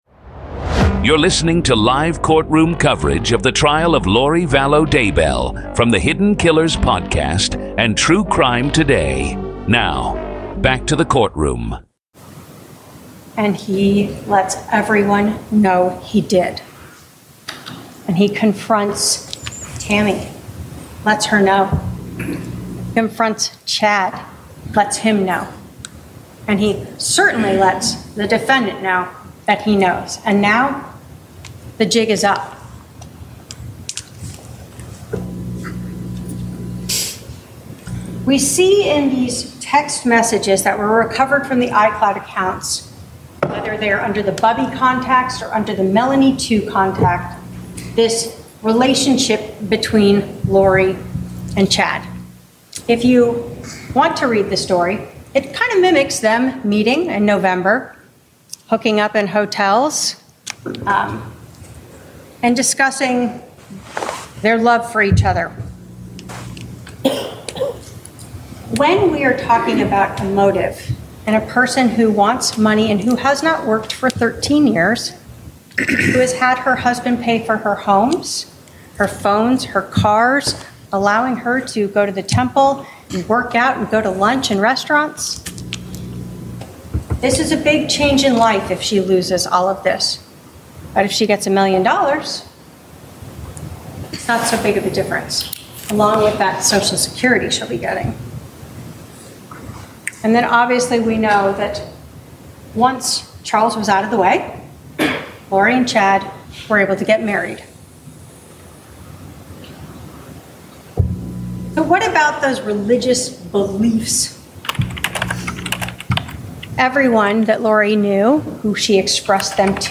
In this episode, you’ll hear the raw courtroom audio from closing arguments in Lori Vallow Daybell’s murder trial—starting with the prosecution’s detailed breakdown of a calculated plan fueled by money, religious delusion, and conspiracy. You’ll also hear Lori herself deliver her own closing argument, defending her actions in a final attempt to sway the jury.